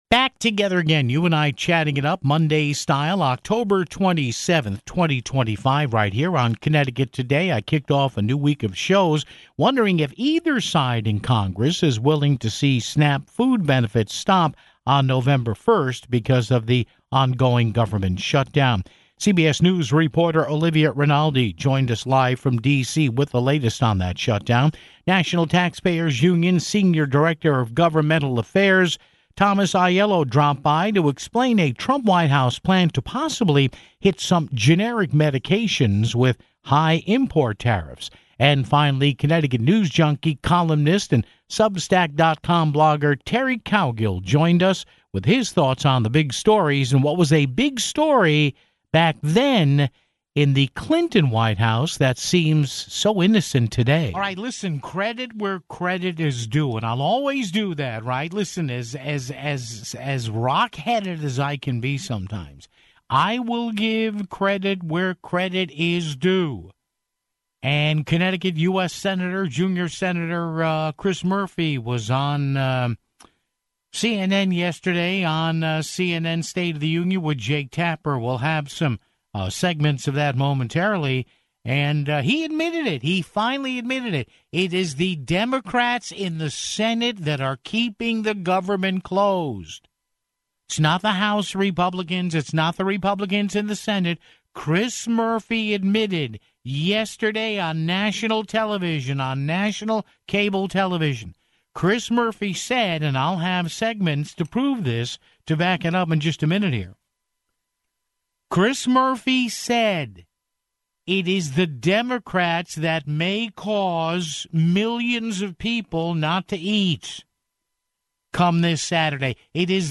CBS News reporter